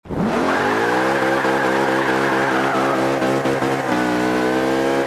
Index of /server/sound/vehicles/lwcars/merc_slk55